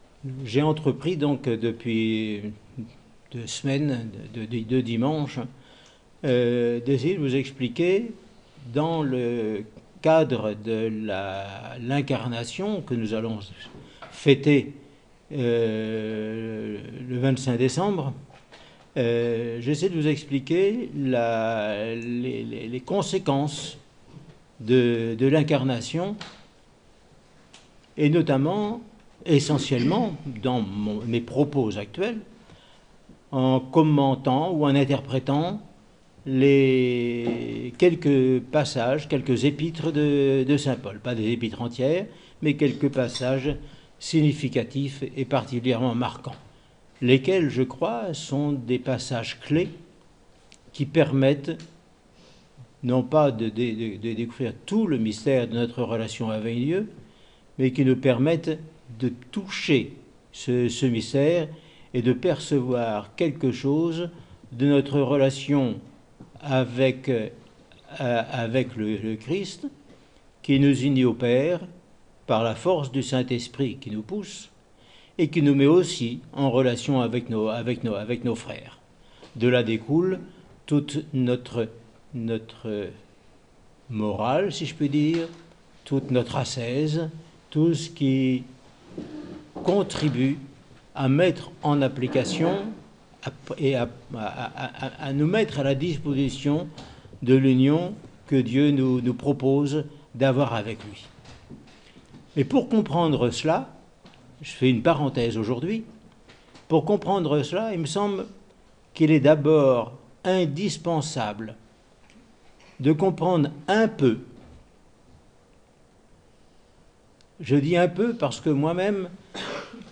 au monastère de la Transfiguration